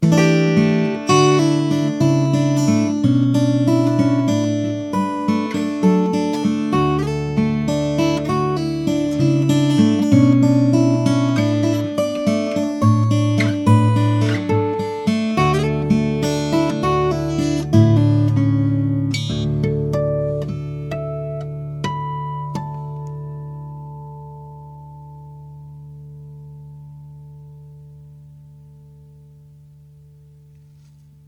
OM Spruce-Ovankol with sound
Bass/mids/trebles/sustain and ring.
Top - German Moonspruce 2012,
Bracing - Lowden Dolphin style
B/S - Flamed Ovankol